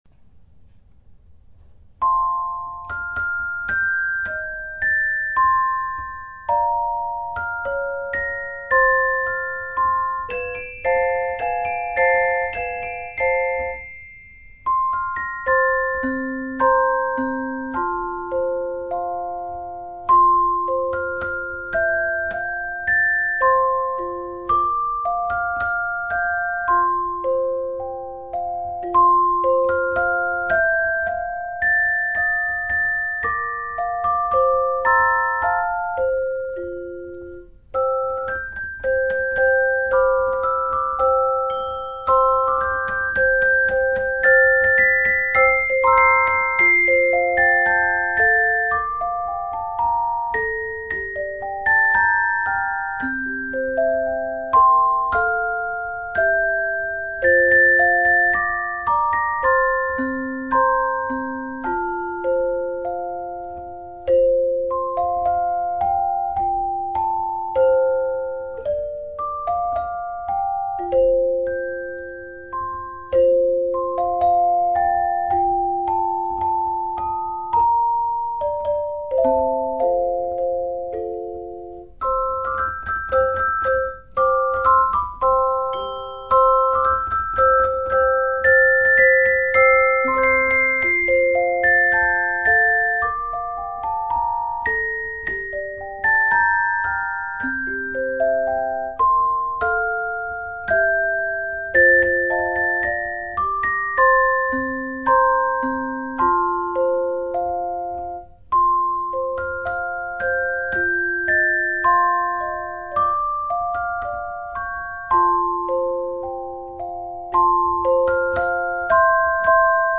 校歌.mp3 "controls="controls"